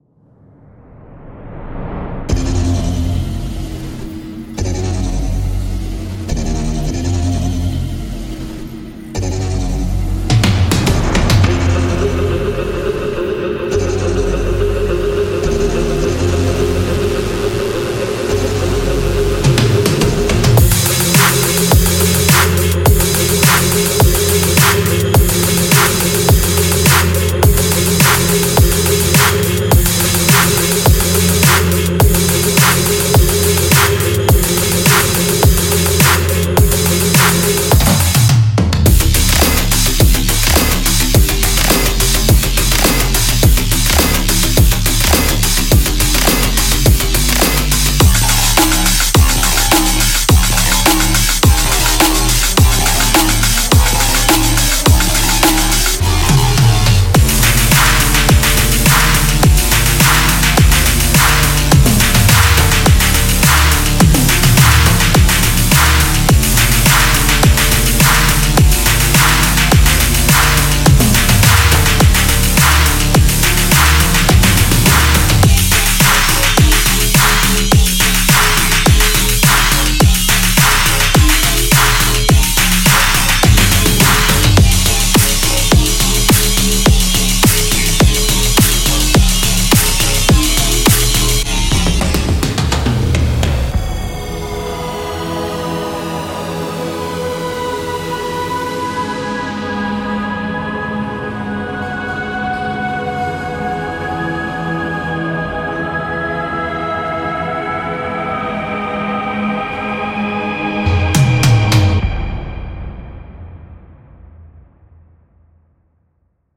10-FX;
30-鼓循环
36-音调（Atmo，Bass，Leads）；